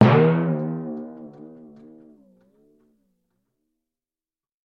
Звуки литавры
Смешной звук литавр (timpani)